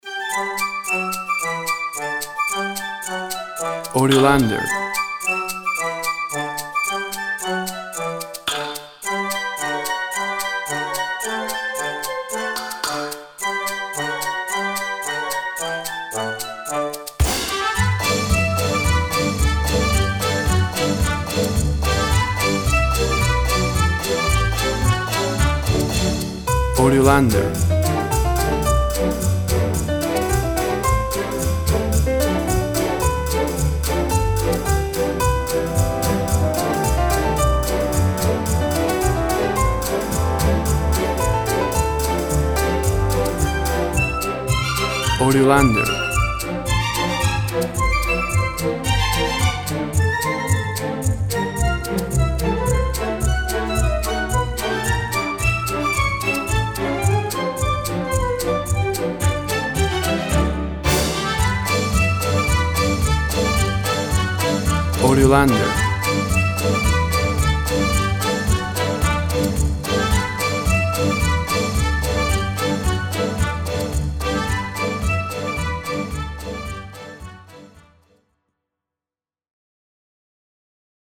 WAV Sample Rate 16-Bit Stereo, 44.1 kHz
Tempo (BPM) 110